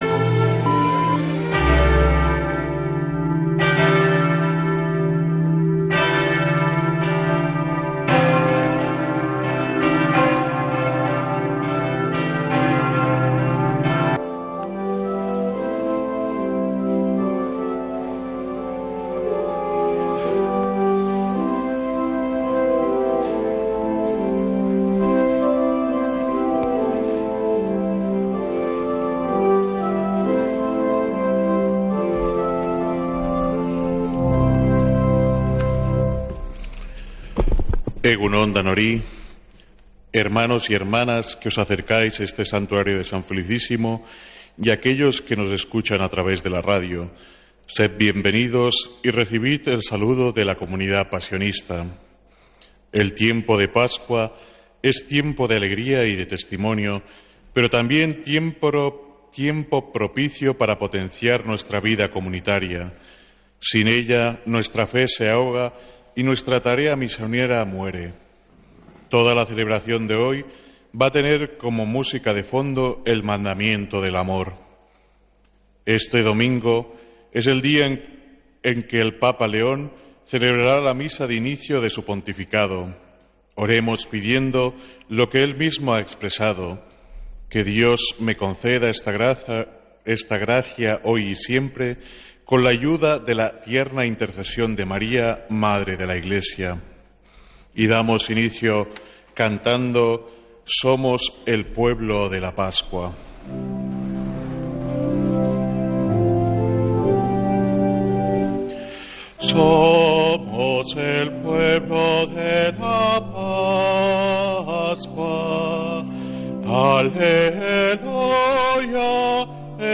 Santa Misa desde San Felicísimo en Deusto, domingo 18 de mayo